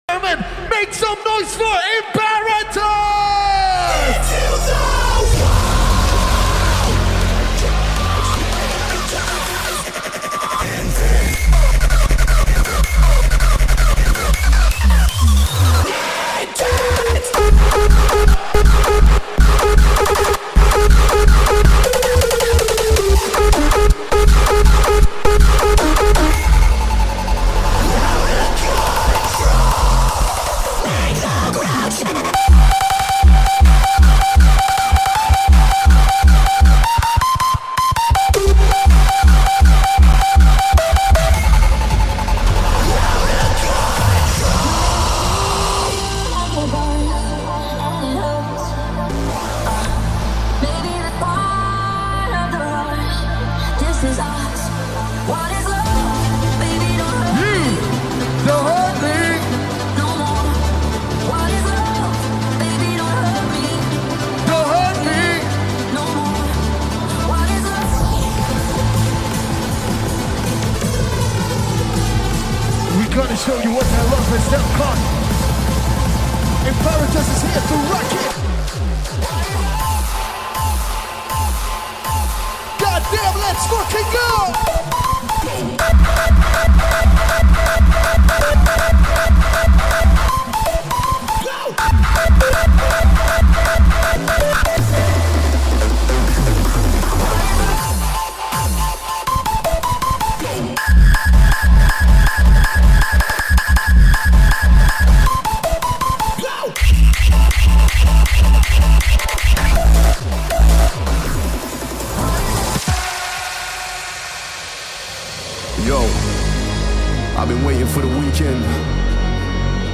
This liveset is embedded on this page from an open RSS feed.